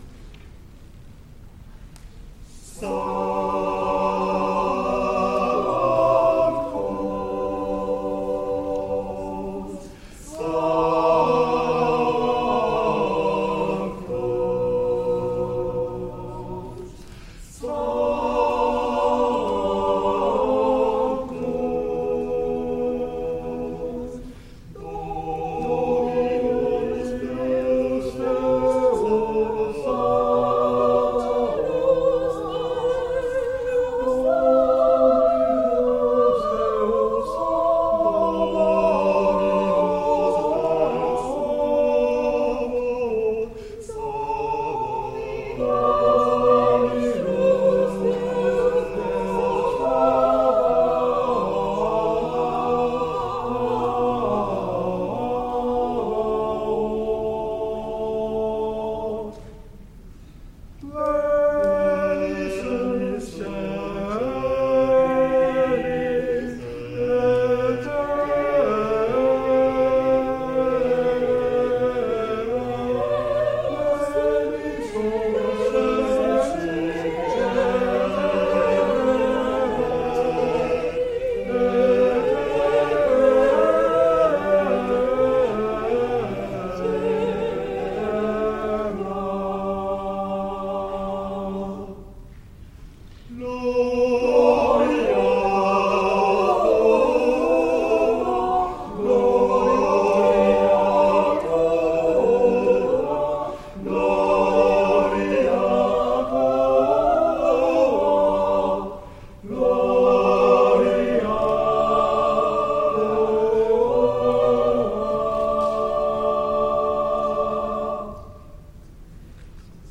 第41回野田市合唱祭
野田市文化会館